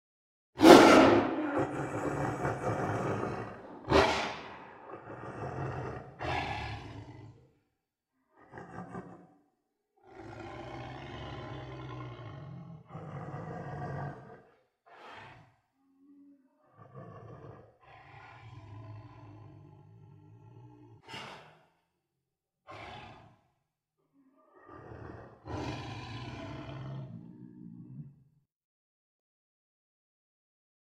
Звуки саблезубого тигра
Грозный рык агрессивного смилодона